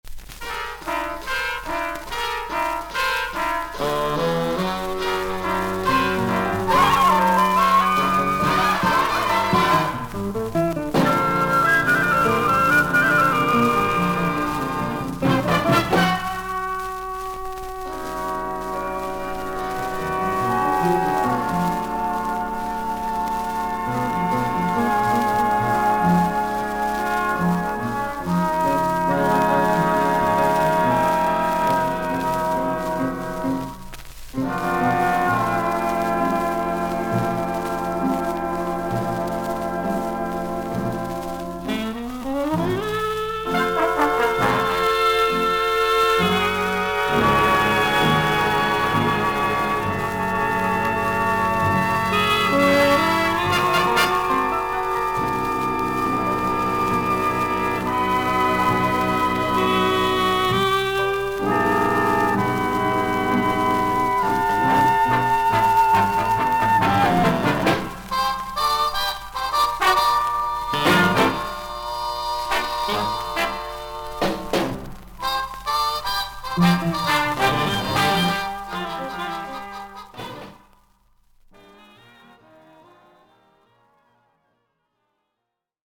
ジャズ・サックス奏者/バンドリーダー。
VG+ 少々軽いパチノイズの箇所あり。少々サーフィス・ノイズあり。